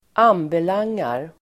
Uttal: [²'an:belang:ar]